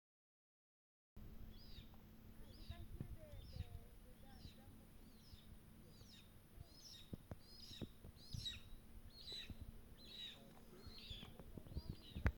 Gavião-de-costas-vermelhas (Geranoaetus polyosoma)
Condição: Selvagem
Certeza: Fotografado, Gravado Vocal